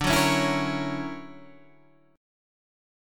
D+M9 chord